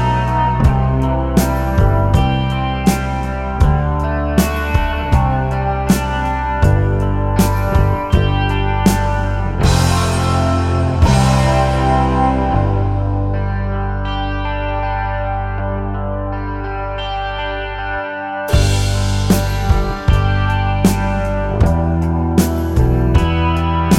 no Backing Vocals Soft Rock 3:52 Buy £1.50